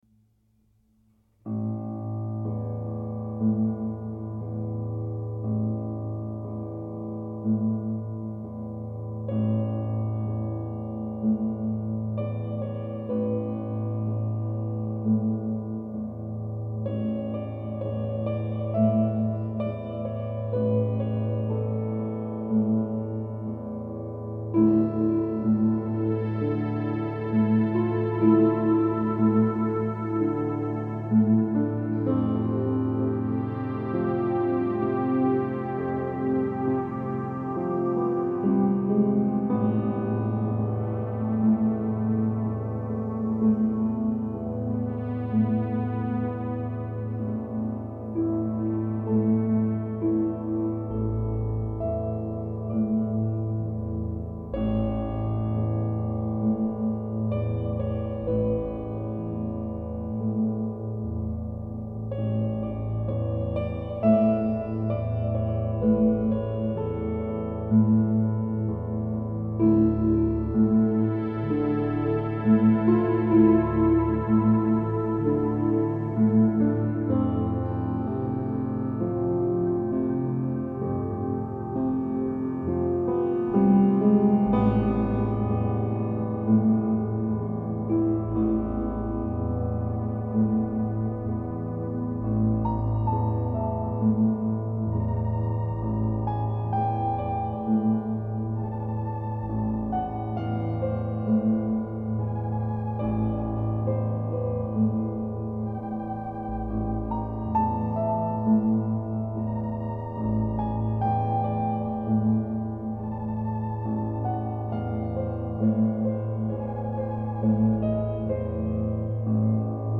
This piece has a “cloudy sky at dusk” vibe to it.
I cut the piano first and then overdubbed minimoog bass line on top of the piano arpegio.
At one point it goes out of sync from each other. I left the flaw because I like the filter sweep at the end of the take.
At the end I keep a descending line going all the way down the piano keyboard while keeping the left hand arpegio going.
All that tonal tension is finally let go with the minimoog filtersweep at the end. some Disolve (sp)
Sad but soothing.